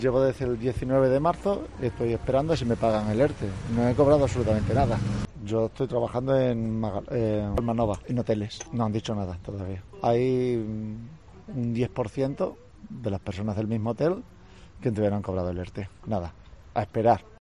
Hombre ERTE